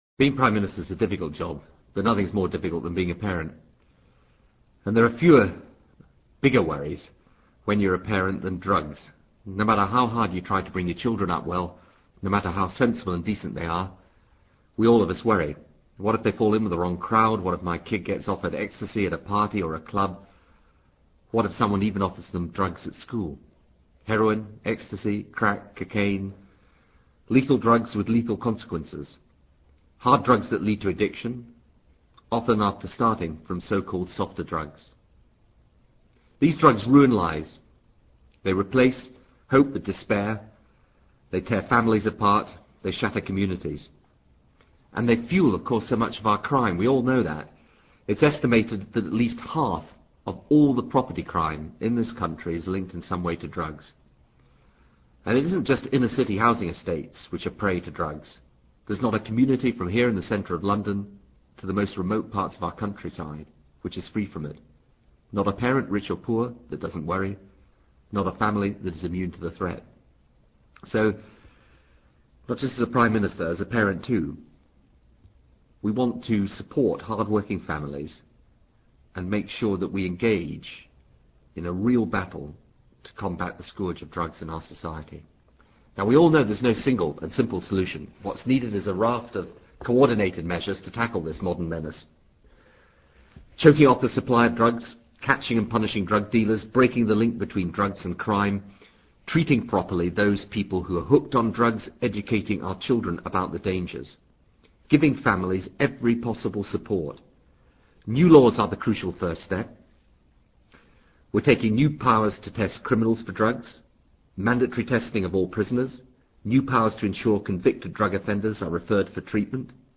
布莱尔首相演讲:Drugs[1]
TRANSCRIPT OF THE PRIME MINISTER'S BROADCAST OF FRIDAY 18 FEBRUARY 2000